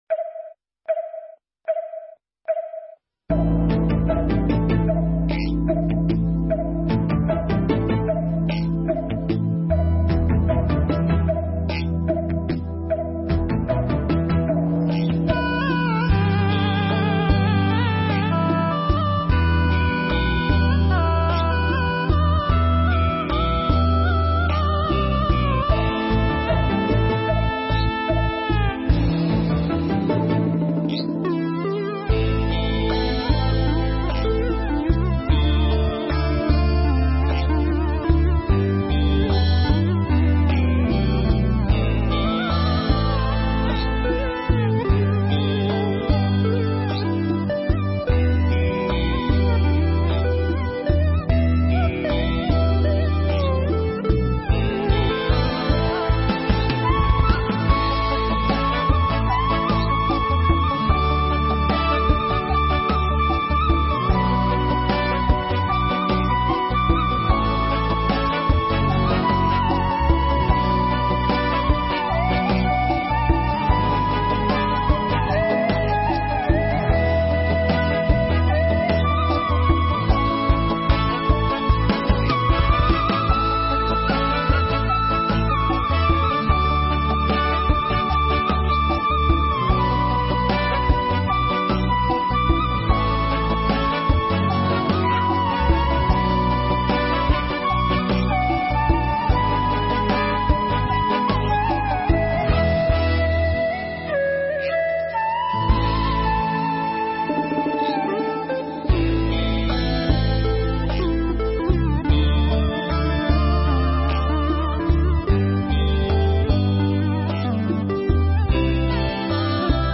Mp3 Pháp Thoại Cần Nhân Đạo Hơn Với Loài Cầm Thú
giảng trong khóa tu Một Ngày An Lạc lần 69 tại Tu Viện Tường Vân